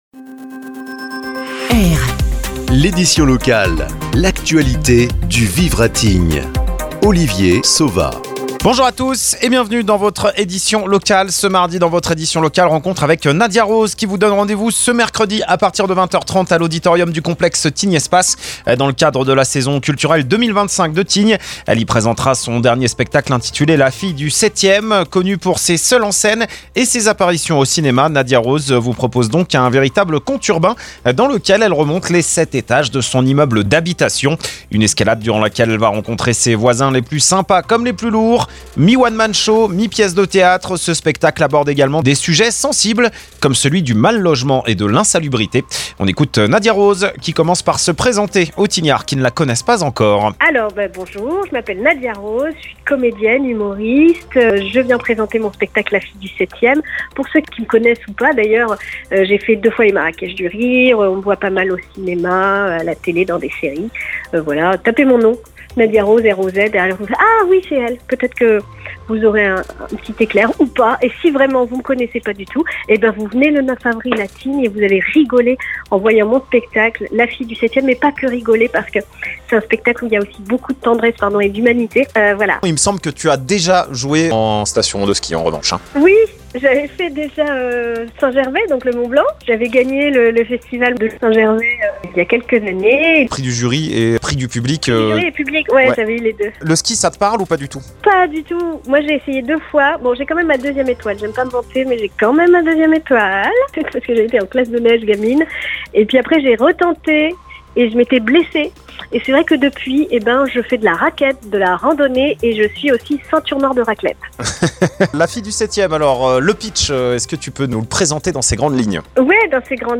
– Ce mardi dans votre édition locale Nadia Roz nous présente « La Fille du 7ème », le spectacle qu’elle interprétera ce mercredi à partir de 20h30 sur la scène du complexe Tignespace dans le cadre de la saison culturelle 2025 de Tignes…